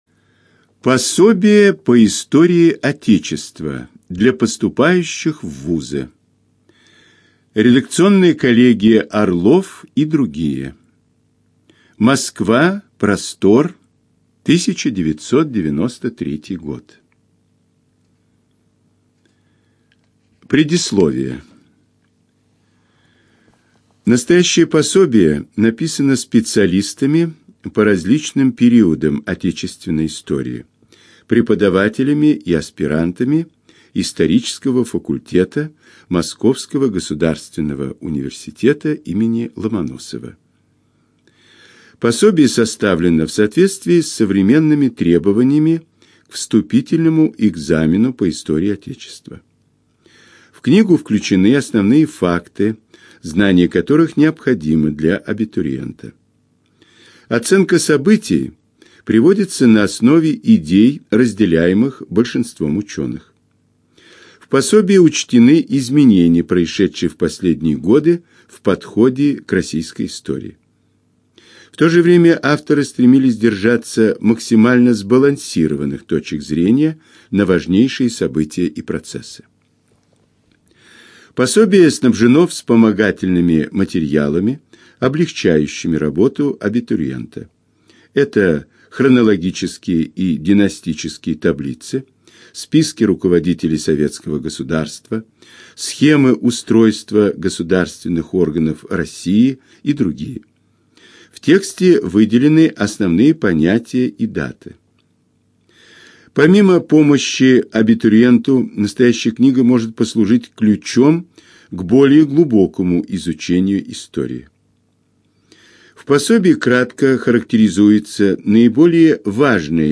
АвторАудиопособия
Студия звукозаписиЛогосвос